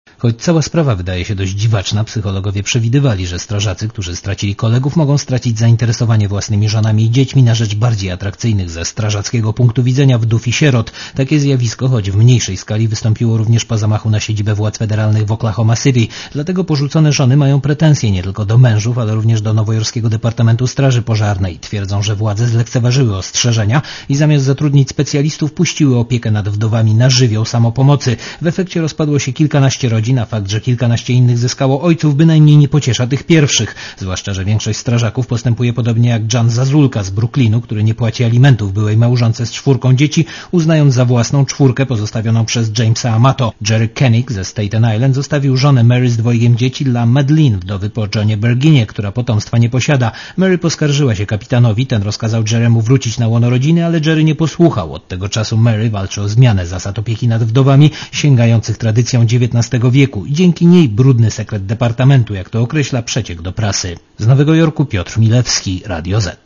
Posłuchaj relacji amerykańskiego korespondenta Radia Zet